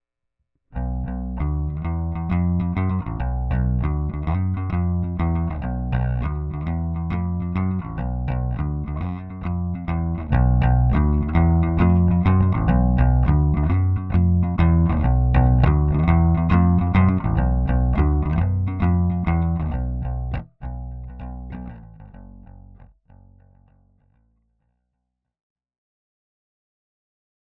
the J48 arrived and my Squier Jazz plugged in, the mic cable plugged in.
Track 1=> inst/interface and Track 2=> J48(micIn). Comparing both dry I could hear a difference, J48 had more clarity.
Of course having the DI-J48 T2 and the T1 playing same time is best :D (sound clip is dry T1 then dry T2 (j48) then both....20second mp3)
j48 bass MIX.mp3